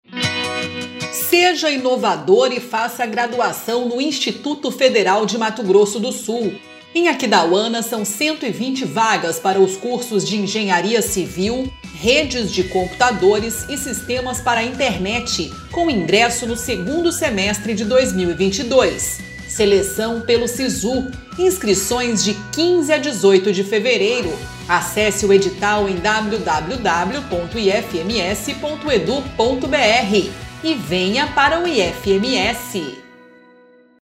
Spot - Cursos de graduação para o 2º semestre de 2022 em Aquidauana